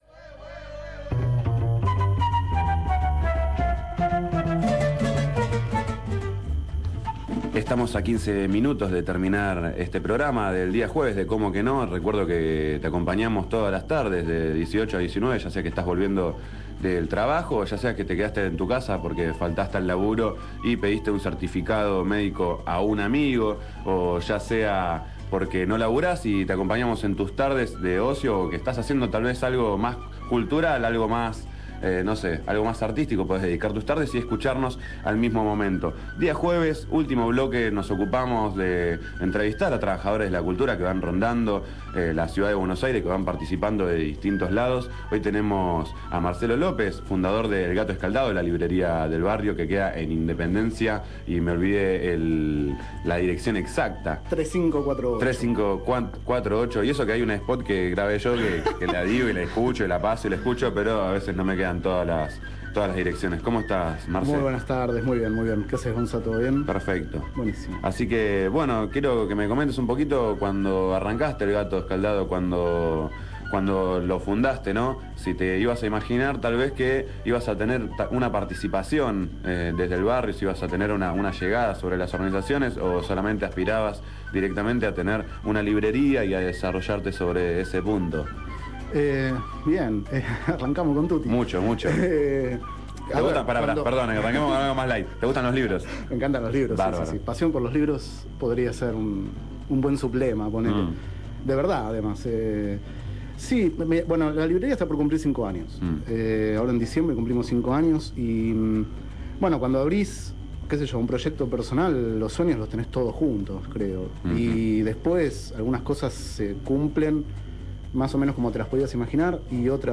Entrevista radial realizada el 27 de octubre de 2011 en el programa Cómo que no?, por FM Boedo